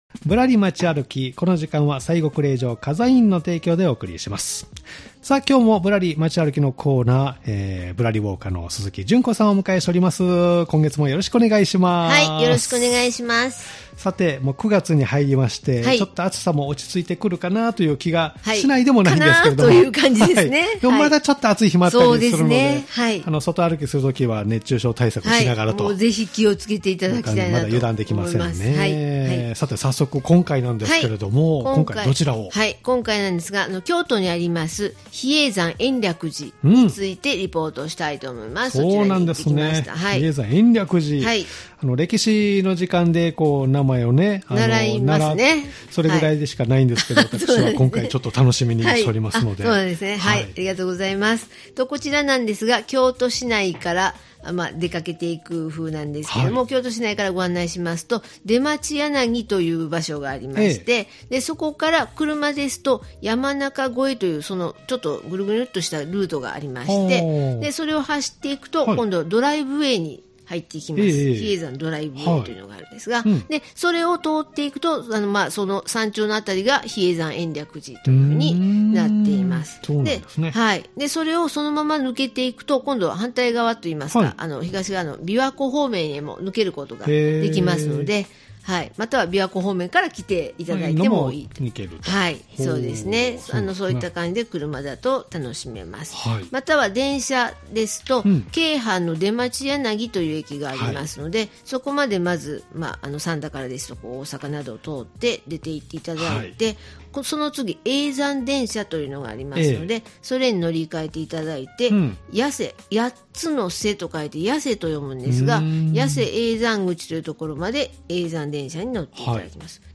今回の「ぶらり、まち歩き」は、滋賀県大津市にある比叡山延暦寺をぶらりした様子をお届けします。